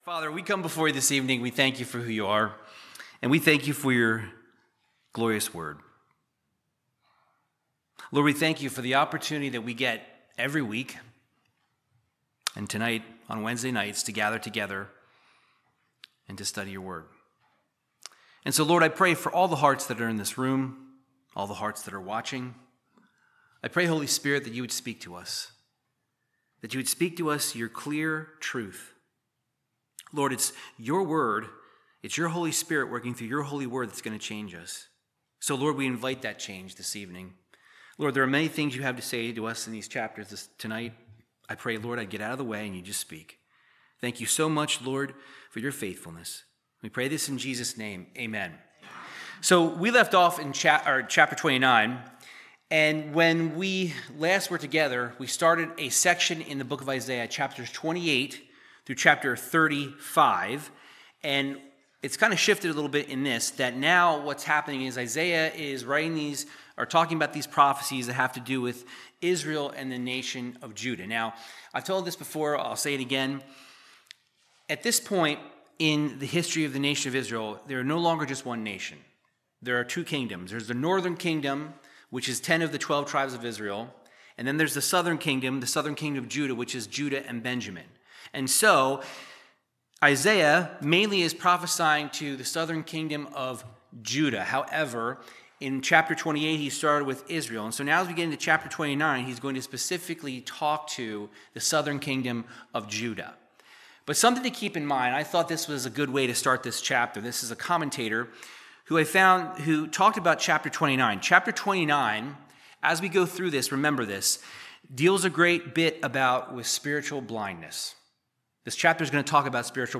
Verse by verse Bible teaching in the book of Isaiah chapter 29